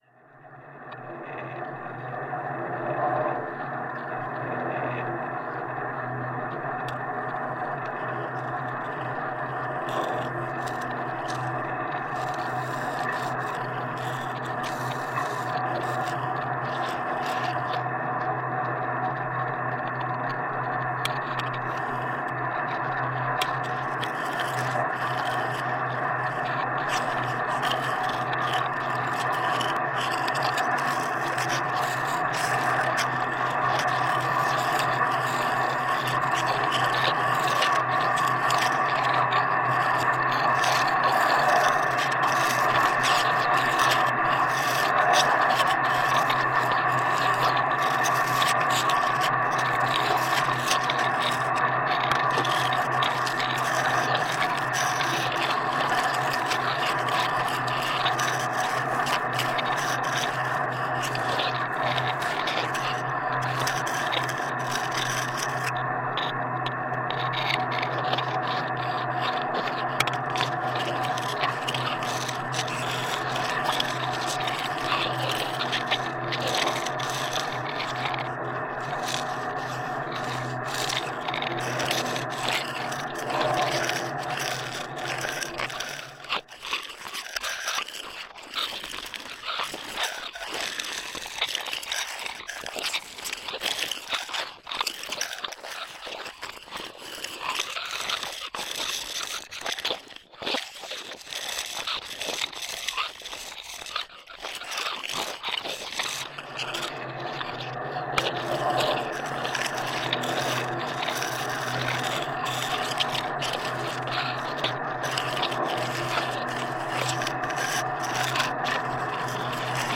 No último dia de gravações testou-se igualmente a utilização de microfones de contacto ou piezo-eléctricos, através de uma improvisação com os carris do funicular da cidade. Gravado com Edirol R44 e dois microfones piezo-eléctricos.
Tipo de Prática: Arte Sonora
Viseu-Rua-da-Ponte-de-Pau-Improvisação-nos-carris-do-funicular.mp3